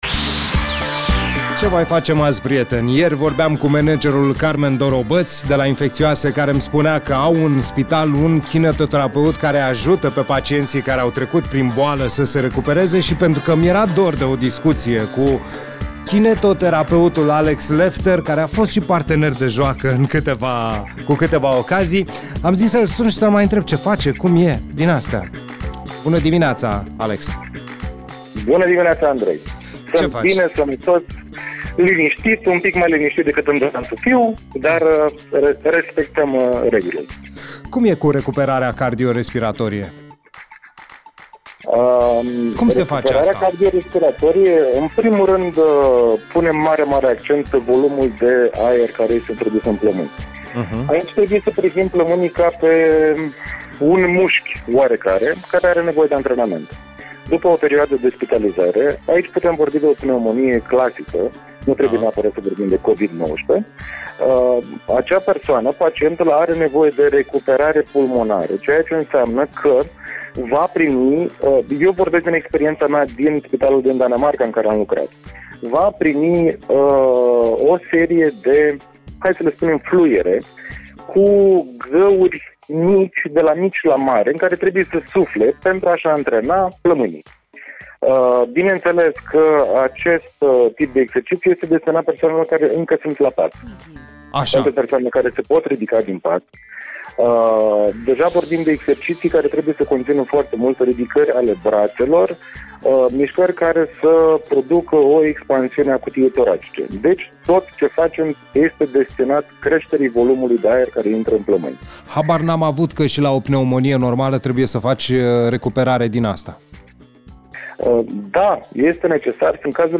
Mai multe detalii din interviul de mai jos: